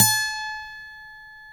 Index of /90_sSampleCDs/Roland L-CD701/GTR_Steel String/GTR_ 6 String
GTR 6-STR20P.wav